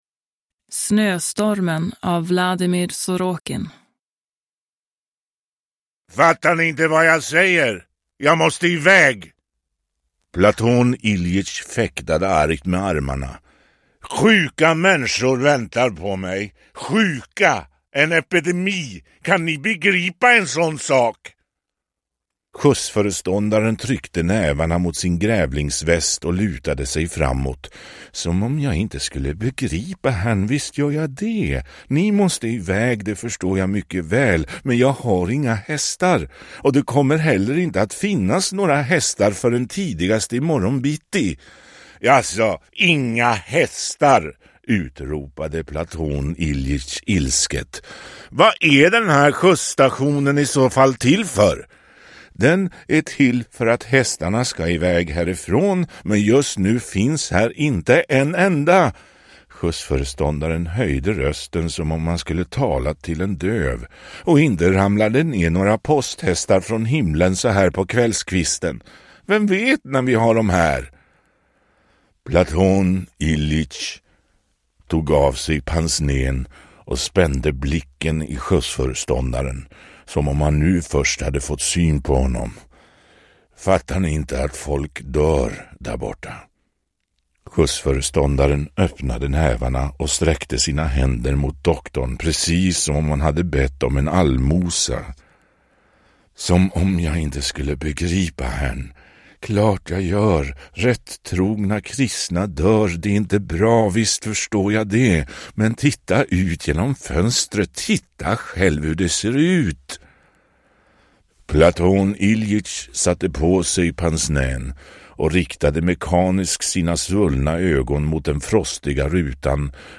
Snöstormen – Ljudbok – Laddas ner
Uppläsare: Rolf Lassgård